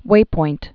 (wāpoint)